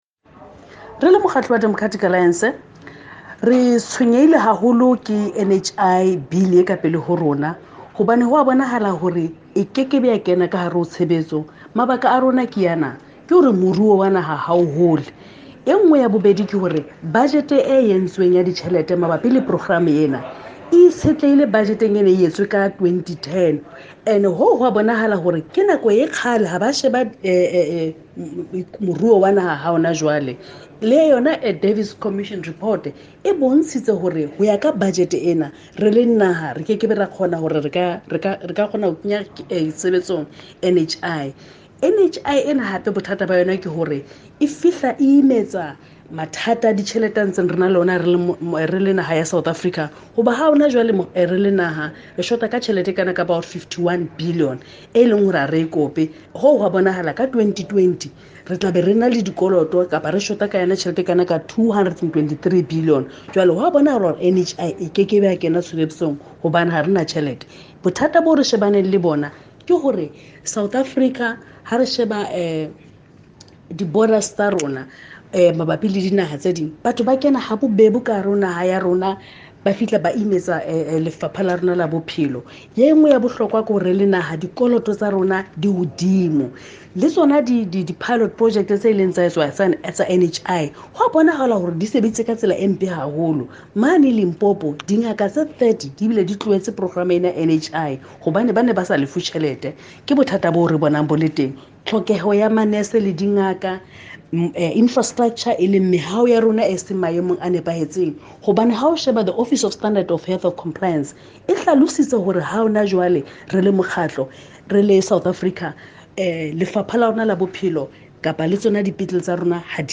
Please find attached a soundbite in
Sesotho by DA Shadow Minister of Health, Patricia Kopane MP.